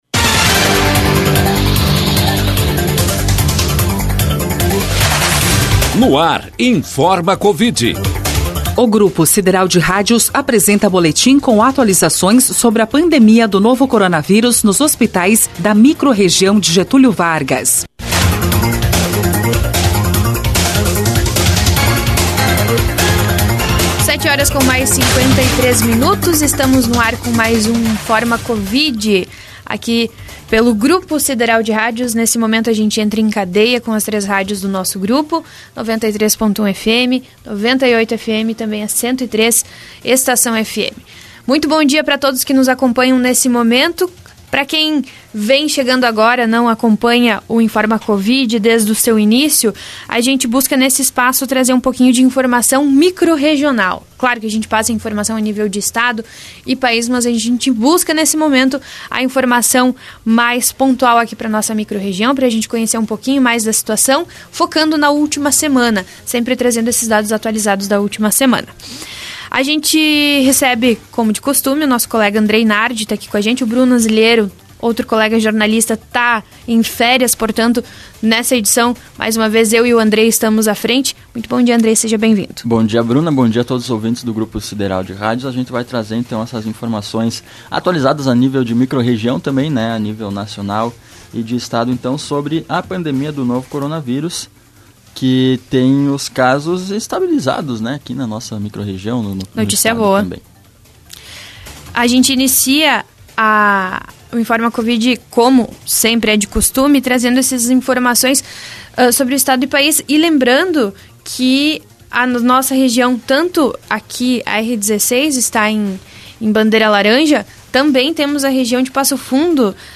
Na última terça-feira (1º) foi ao ar mais uma edição do Informa Covid, boletim semanal com atualizações sobre a Covid-19, doença causada pelo novo coronavírus, na microrregião de Getúlio Vargas.